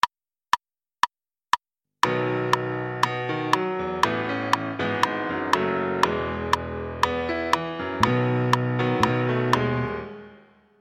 Chant et Piano